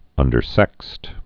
(ŭndər-sĕkst)